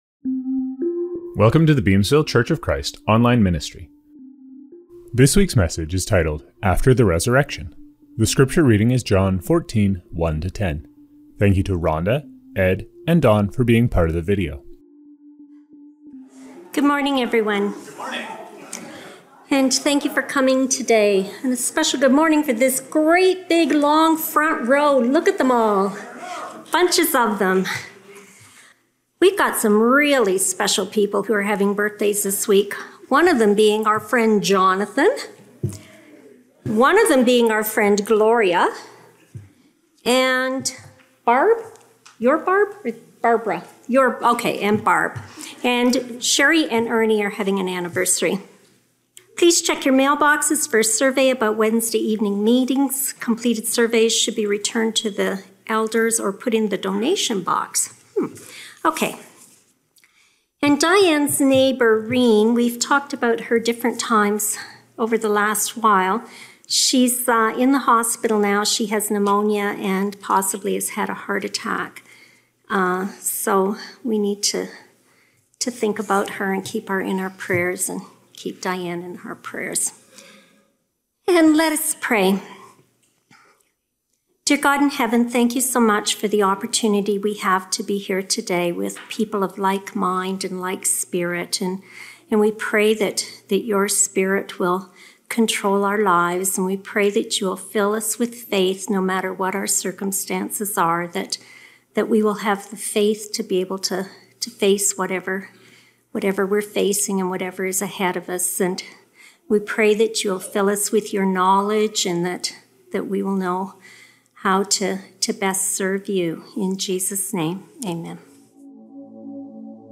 Scriptures from this service: Communion - Romans 5:15-17 (NRSV). Reading - John 14:1-10 (NIV).